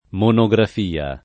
[ mono g raf & a ]